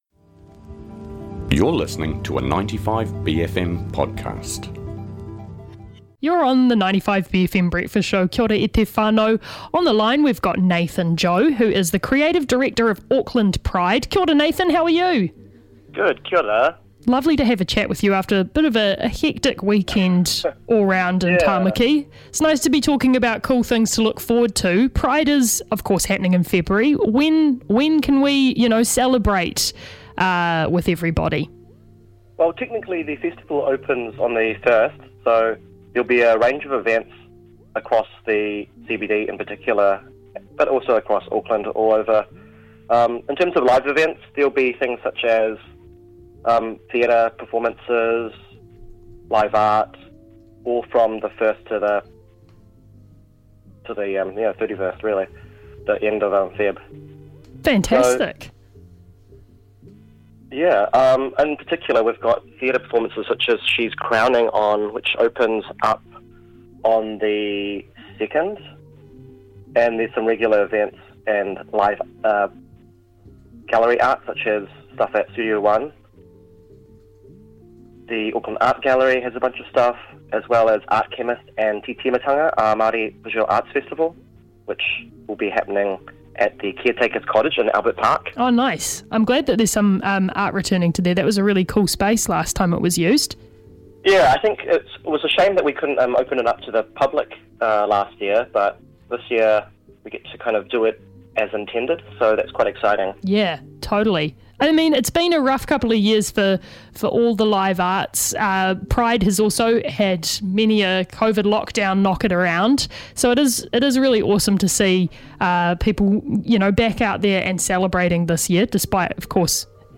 phones up for a kōrero about what to expect from this year's festival.